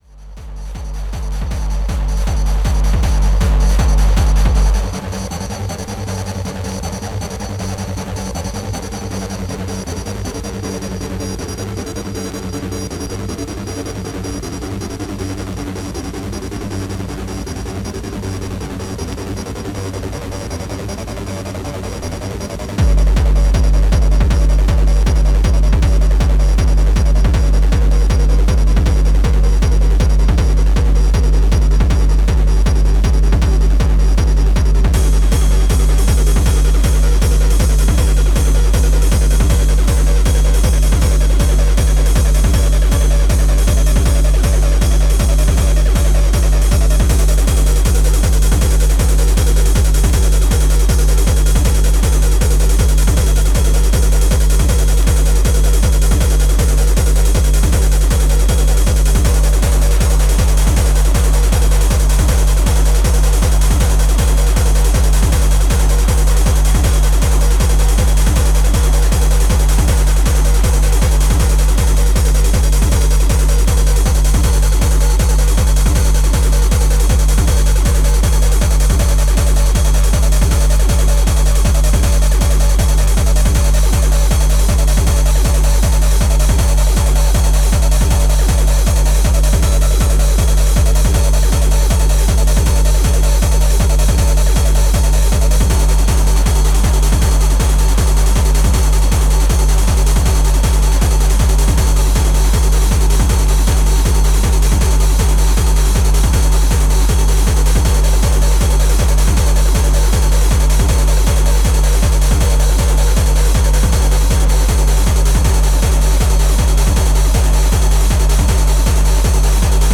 Hardtek/Tekno